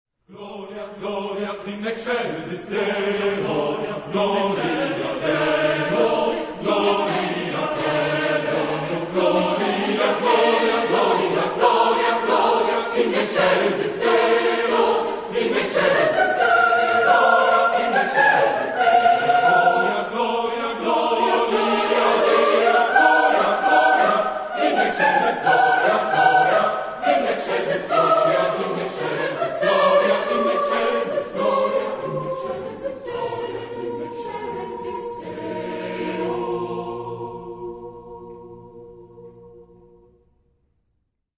Genre-Style-Form: Sacred ; Hymn (sacred)
Mood of the piece: fast ; rhythmic ; prayerful ; exciting
Type of Choir: SSAATTBB  (8 mixed voices )
Tonality: A flat major ; B flat major ; A flat minor
Discographic ref. : 6. Deutscher Chorwettbewerb, 2002